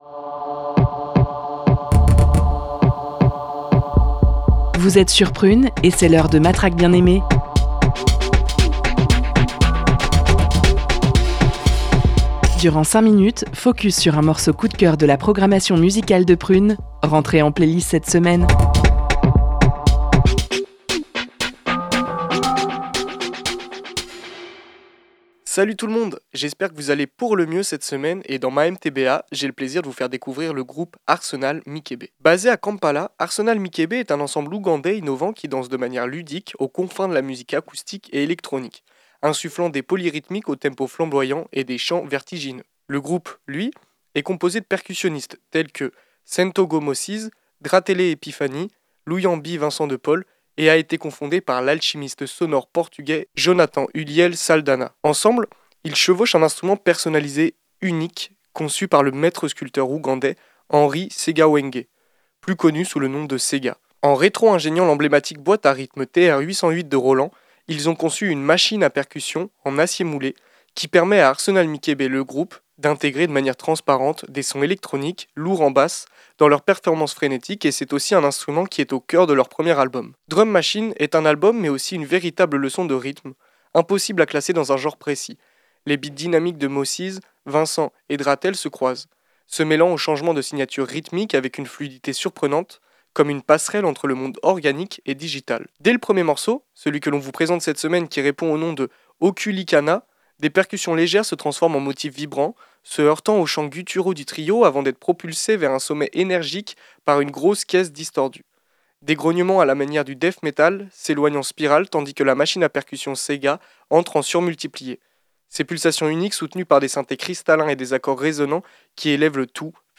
percussionnistes